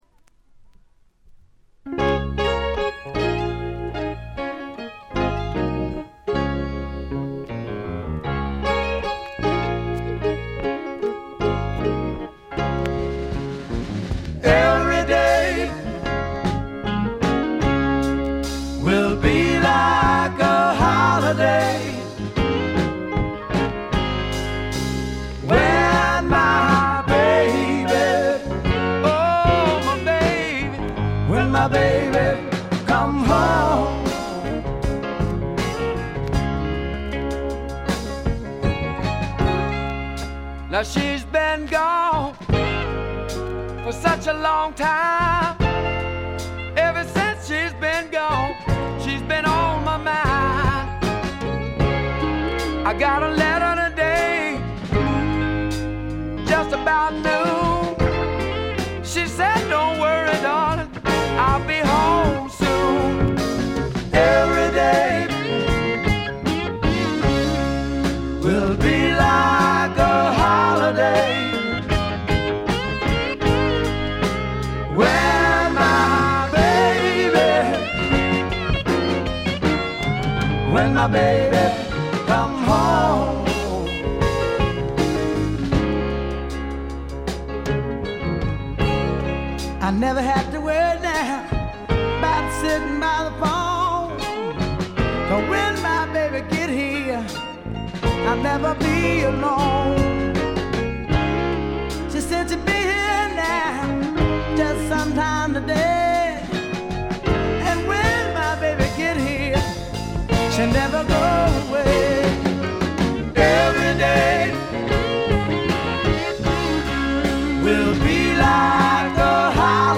静音部での微細なバックグラウンドノイズ、散発的なプツ音軽いものが2回ほど。
びしっと決まった硬派なスワンプ・ロックを聴かせます。
試聴曲は現品からの取り込み音源です。
Recorded at Paramount Recording Studio.